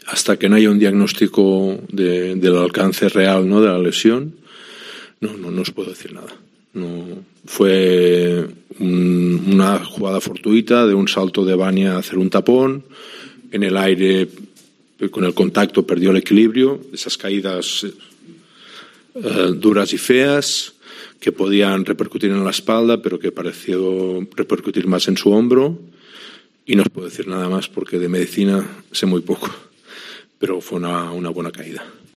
Tampoco el entrenador Jaume Ponsarnau ha querido ser más preciso en torno a los plazos, pero sí ha explicado en rueda de prensa cómo se produjo esta lesión: "hasta que no haya un diagnóstico del alcance real no os puedo decir nada. Fue una jugada fortuita cuando trató de hacer un tapón, en el aire perdió el equilibrio y fue una de esas caídad duras y feas que podían repercutir en la espalda, pero fue más en su hombro y no os puedo decir nada más porque de medicina sé muy poco".